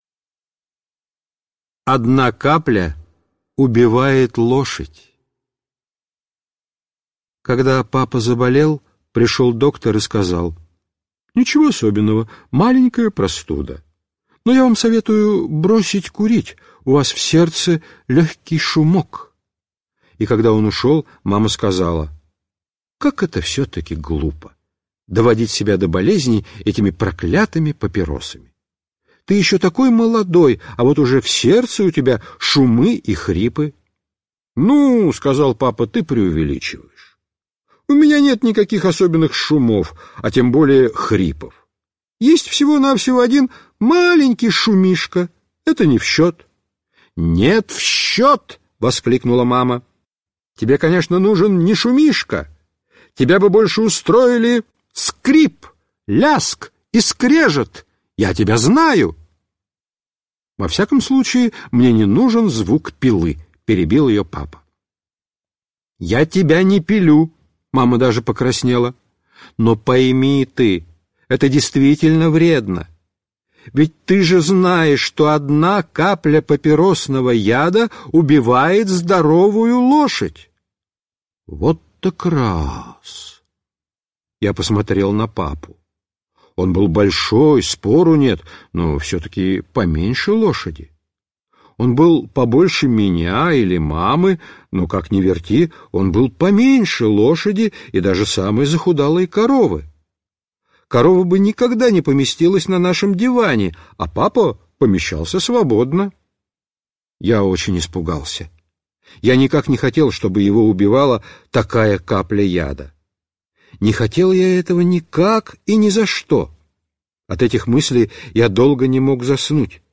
Аудиорассказ «Одна капля убивает лошадь»